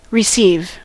receive: Wikimedia Commons US English Pronunciations
En-us-receive.WAV